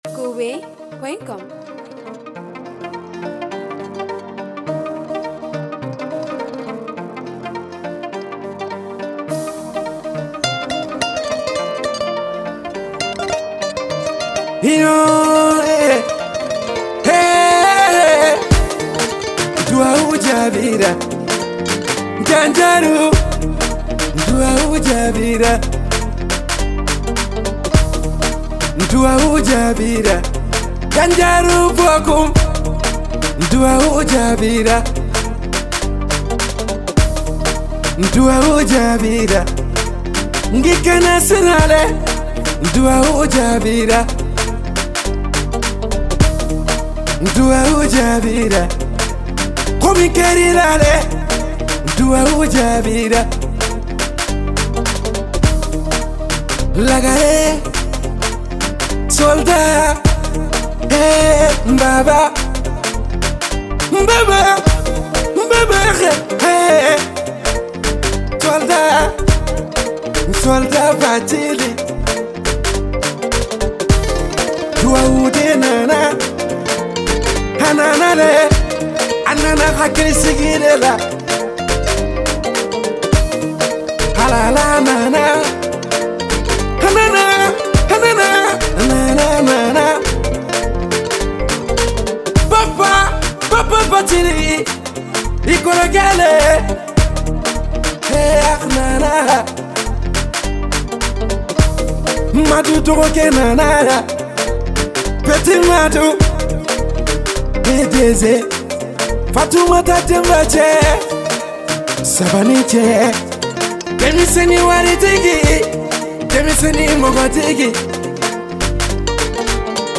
musique Mali world.